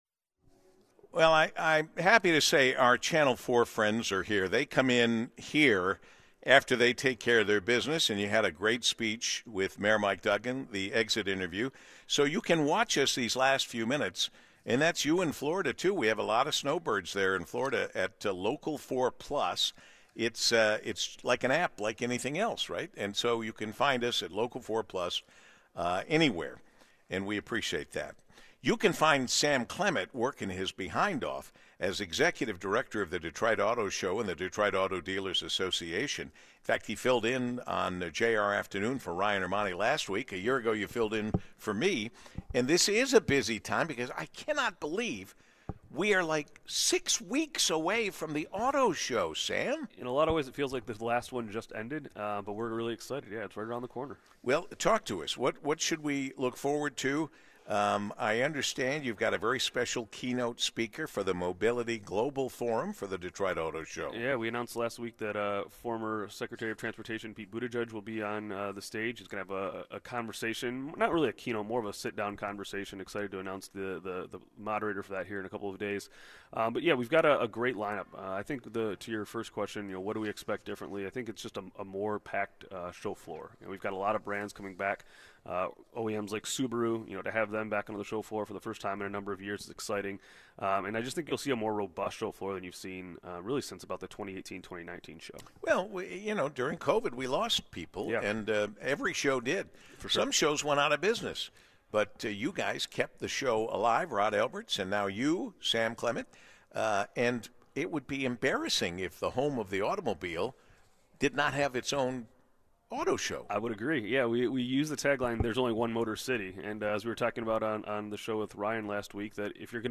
live from the Detroit Economic Club